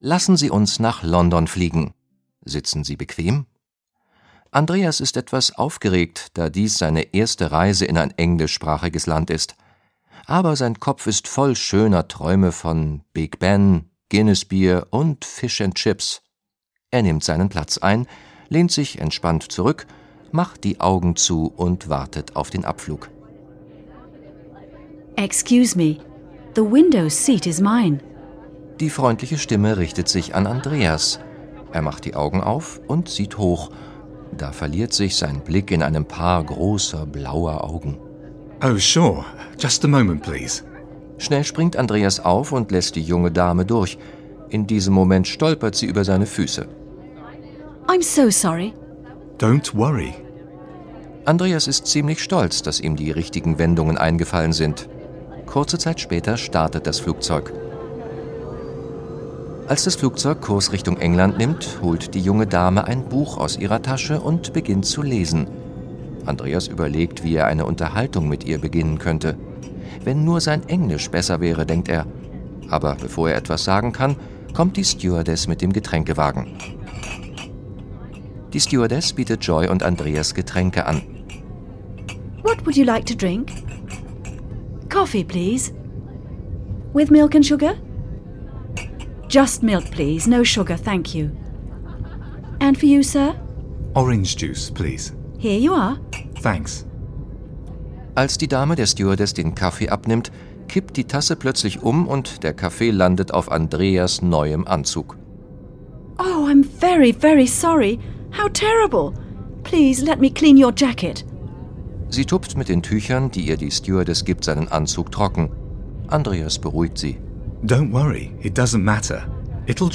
Der Sprachkurs zum Hören mit 4 Audio-CDs und Begleitheft
Unterhaltsame Dialoge und effektive Übungen trainieren Hören und Sprechen.
Dialoge mit Übersetzung